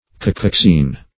Kakoxene \Ka*kox"ene\